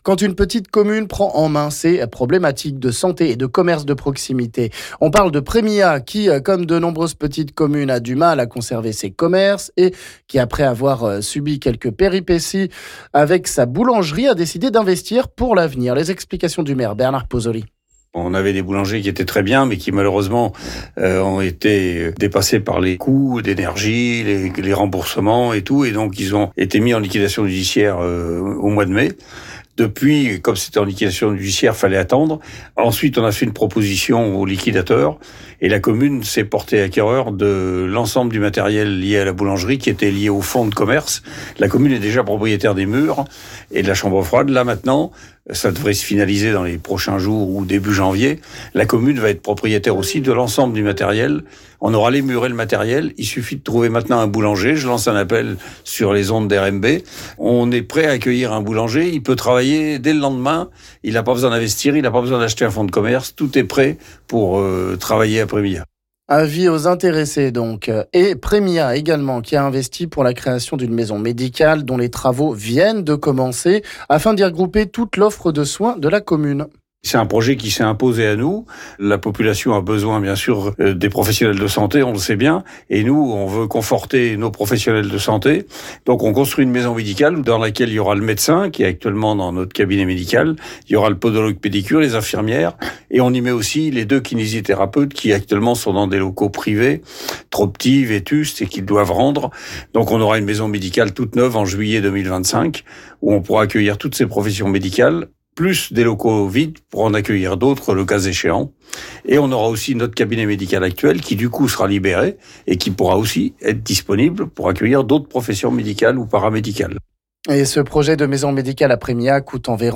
On en parle avec le maire de Prémilhat Bernard Pozzoli...